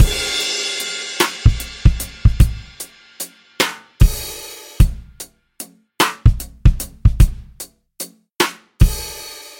Swing Beatbox Loop
描述：只是简单的beatbox循环
Tag: 120 bpm Jazz Loops Beatbox Loops 2.70 MB wav Key : Unknown